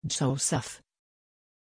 Pronunciation of Joseph
pronunciation-joseph-sv.mp3